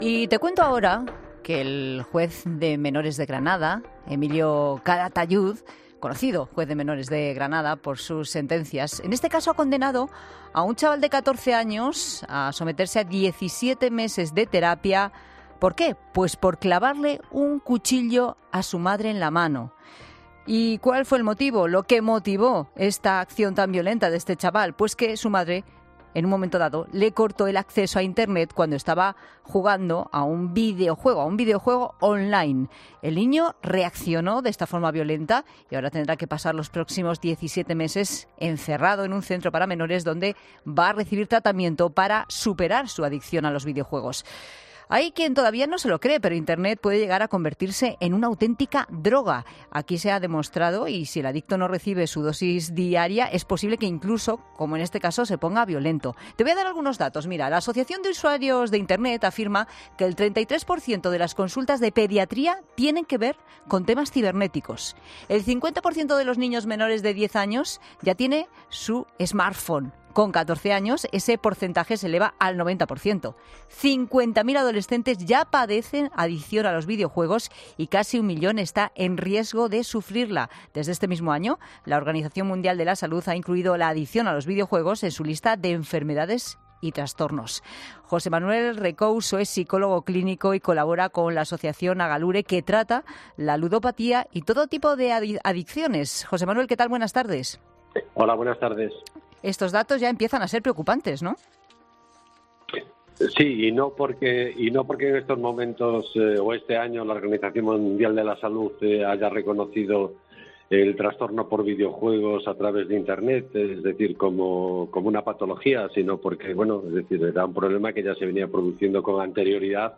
Un psicólogo: "La adicción a los videojuegos es cada vez más habitual"